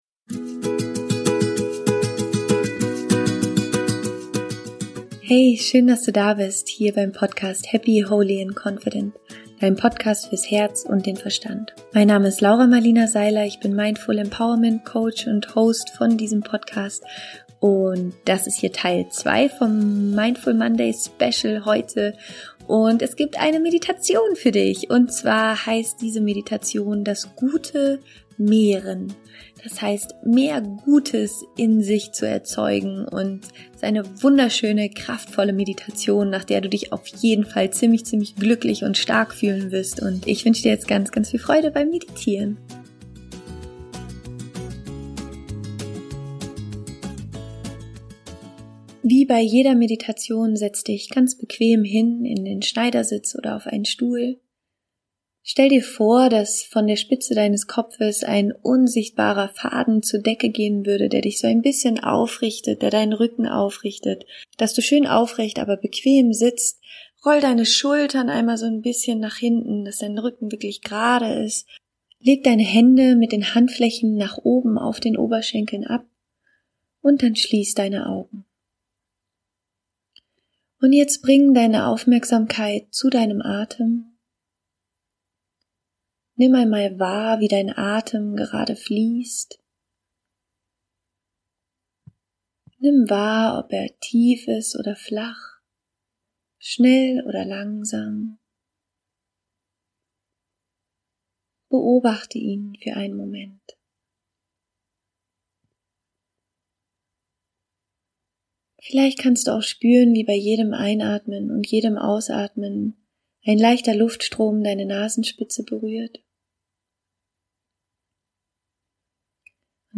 Das Gute verstärken - Meditation (Teil 2)